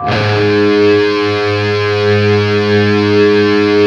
LEAD G#1 LP.wav